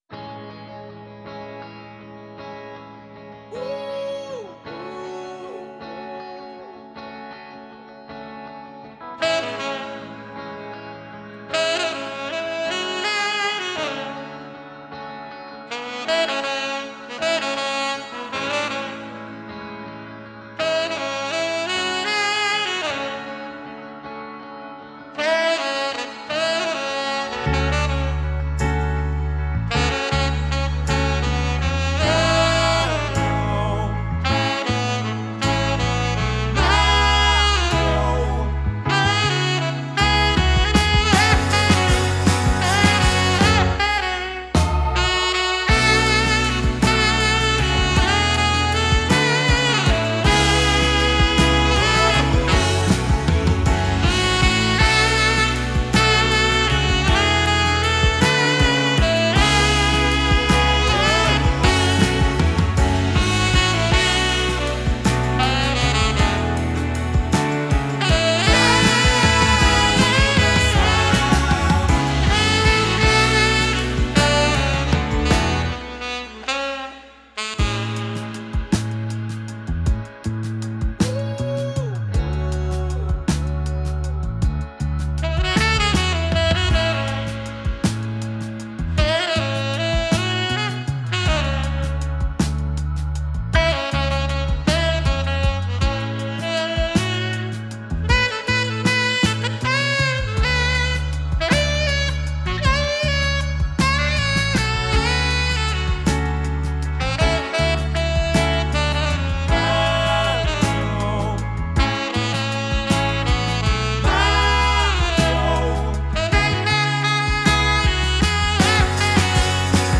Saxophonist